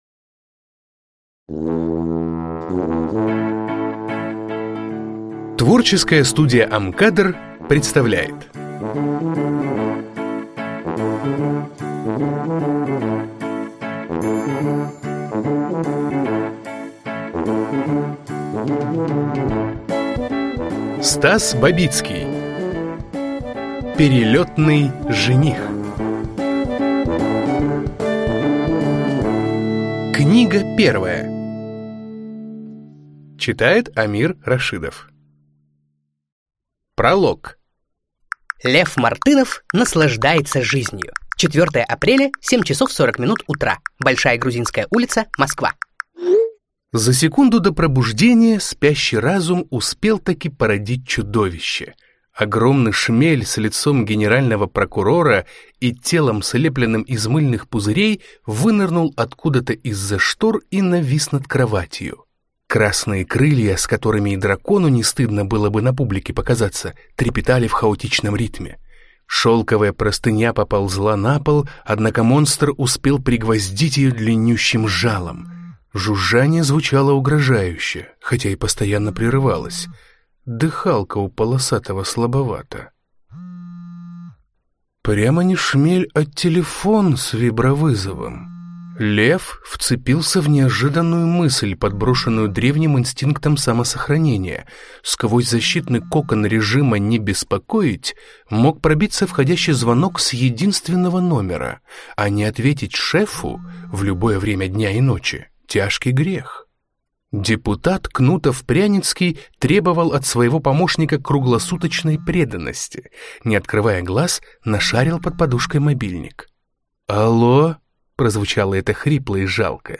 Аудиокнига Перелётный жених. Книга первая | Библиотека аудиокниг